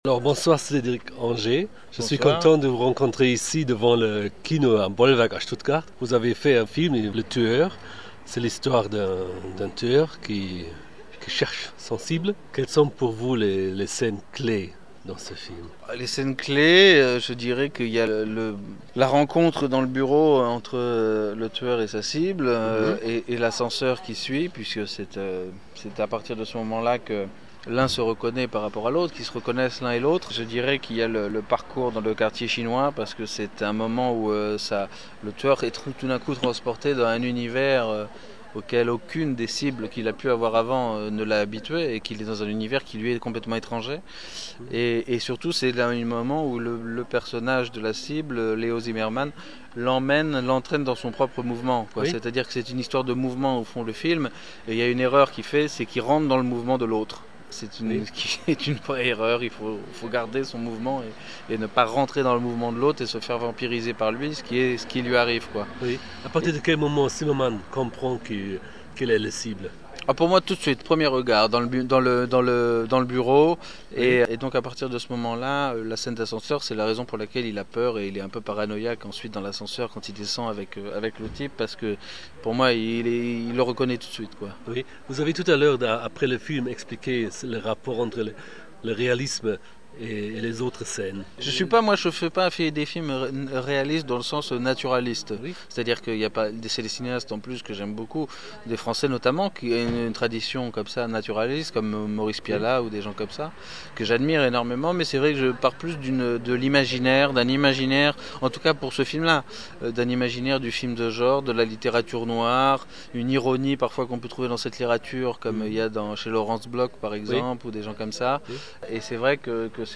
Danach konnte ich mit ihm über seinen Film sprechen: